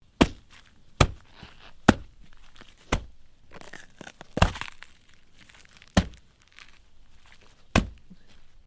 Melon Hits and Smash
yt_Eu7w_27tlBI_melon_hits_and_smash.mp3